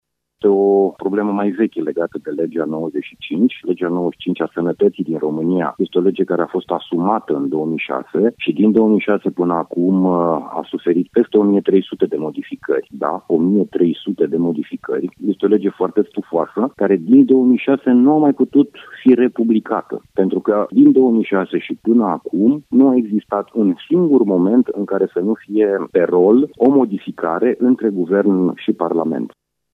În privinţa Legii Sănătăţii, Florin Buicu a spus că este important ca aceasta să fie republicată: